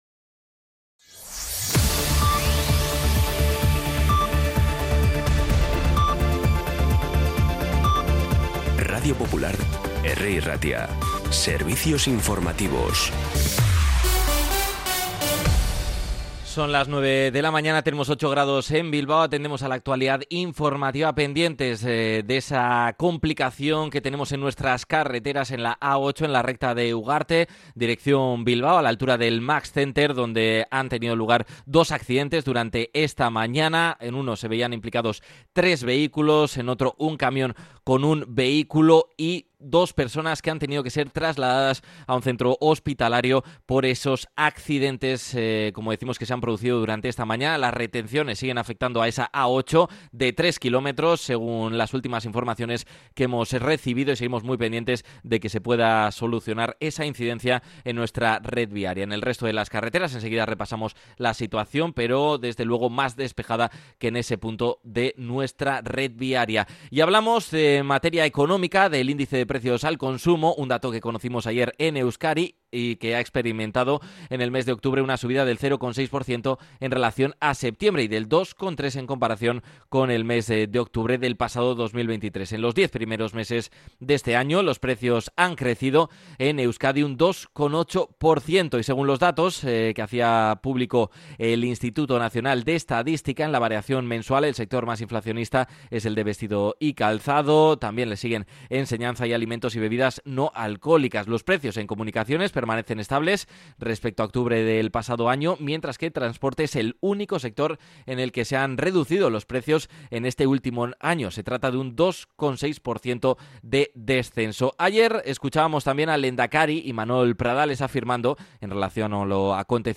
Las noticias de Bilbao y Bizkaia del 15 de noviembre a las 9